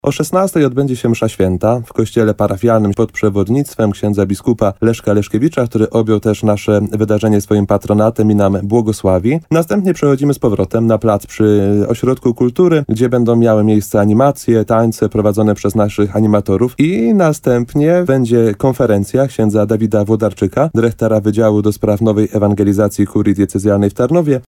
24ksiadz.mp3